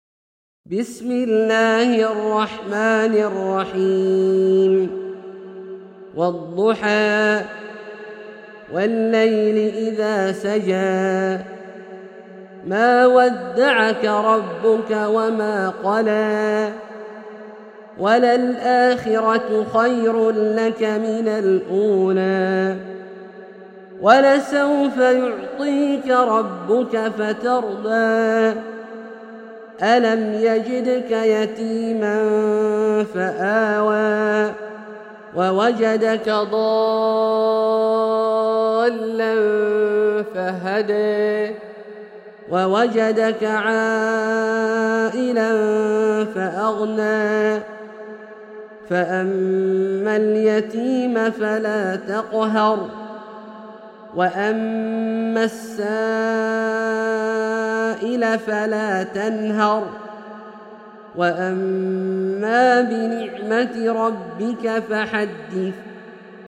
سورة الضحى - برواية الدوري عن أبي عمرو البصري > مصحف برواية الدوري عن أبي عمرو البصري > المصحف - تلاوات عبدالله الجهني